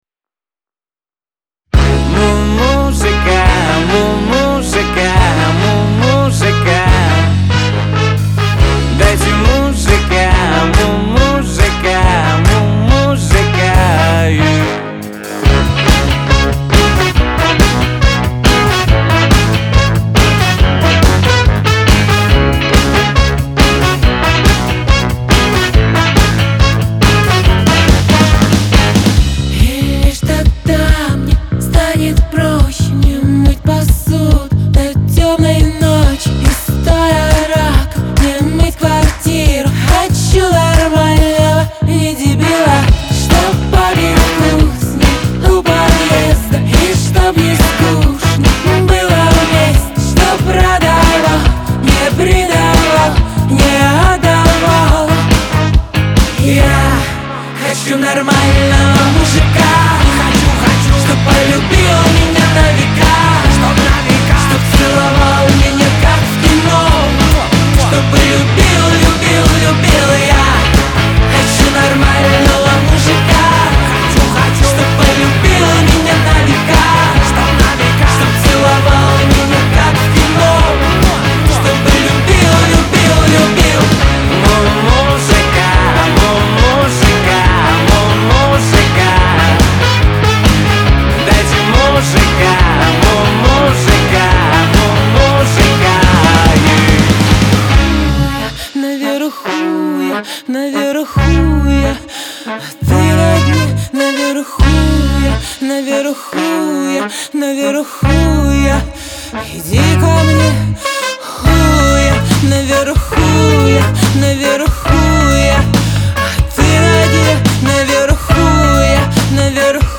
что делает композицию заразительной и танцевальной.